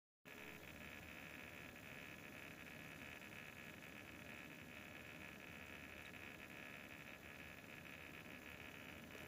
Sie hört sich, egal bei welcher Drehzahl, sehr laut an. Ähnliche wie eine alte mechanische Festplatte.
danke für eure schnellen antworten. anbei mal das geräusch. ich werde dann einfach auf einen luftkühler wechseln und die AiO zurückschicken. mfg und schönes we!